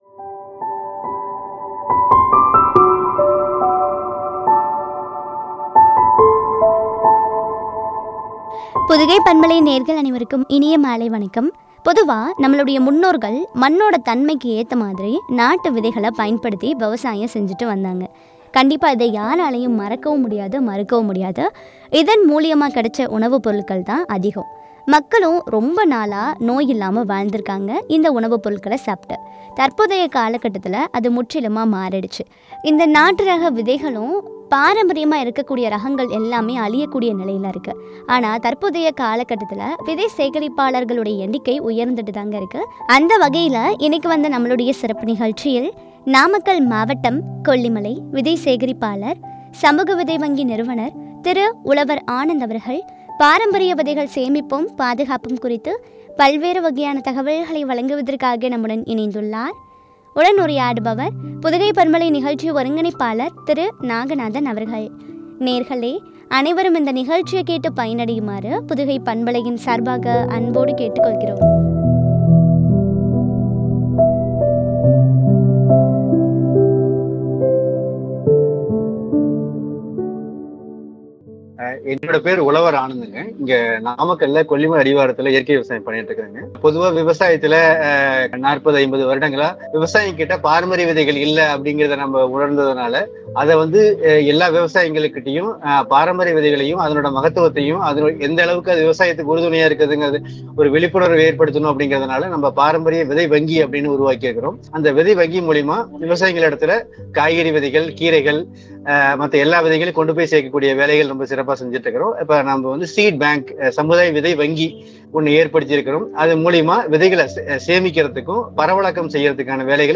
பாதுகாப்பும் குறித்து வழங்கிய உரையாடல்.